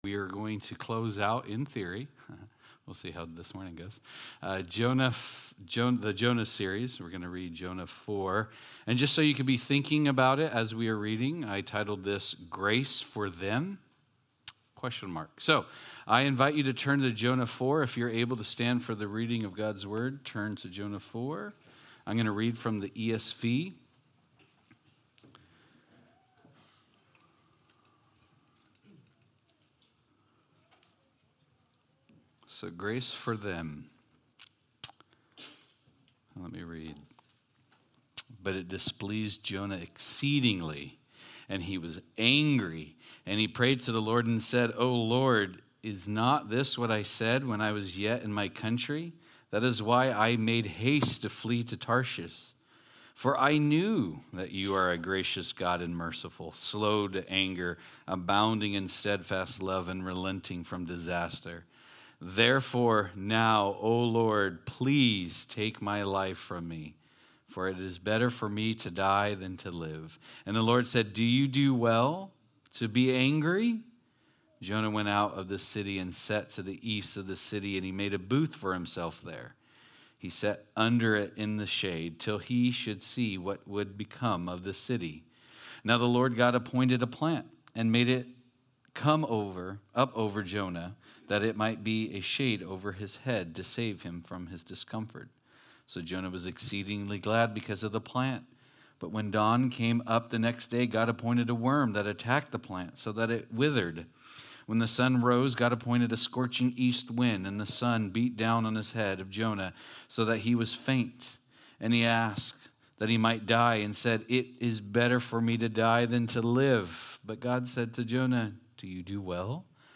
The Rebellious Prophet Service Type: Sunday Morning « Jonah